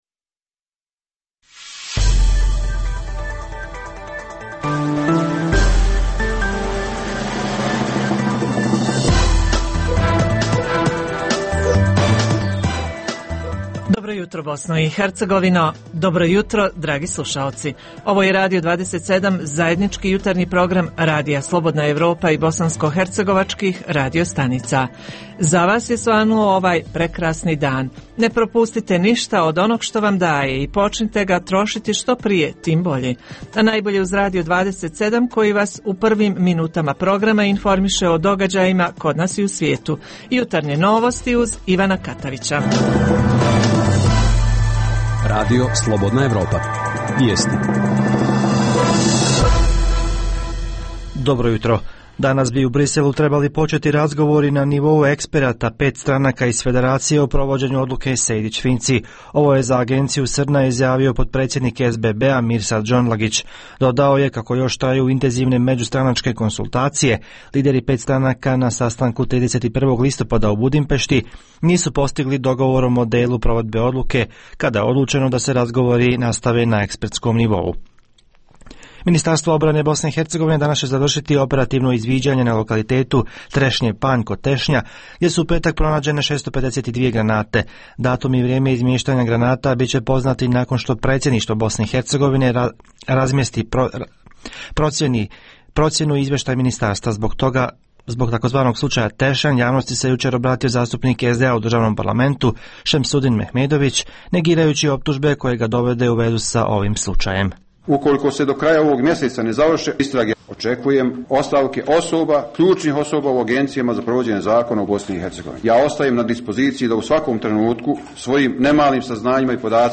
U rubrici „Filmoskop“ možete čuti novosti iz svijeta sedme umjetnosti. Uz tri emisije vijesti, slušaoci mogu uživati i u ugodnoj muzici.